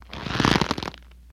Leather Twist Whoosh